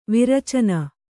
♪ vairōcana